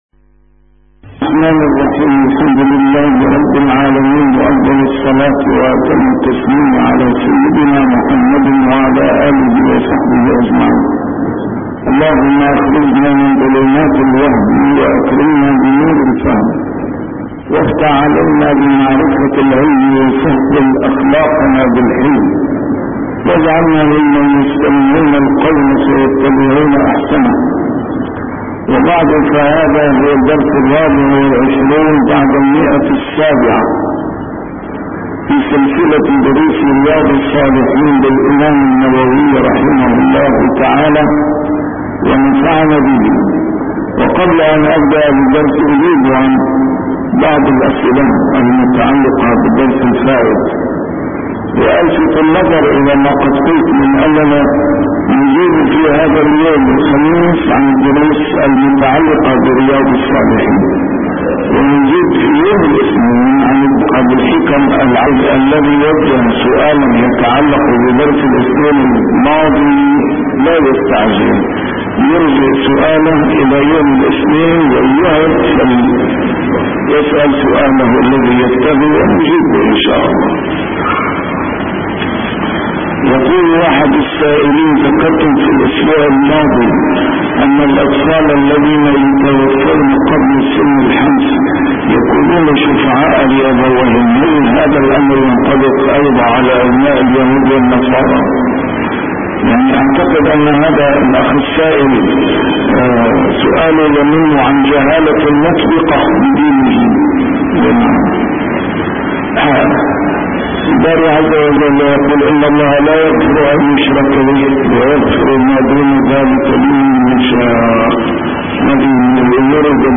A MARTYR SCHOLAR: IMAM MUHAMMAD SAEED RAMADAN AL-BOUTI - الدروس العلمية - شرح كتاب رياض الصالحين - 724- شرح رياض الصالحين: من مات له أولاد صغار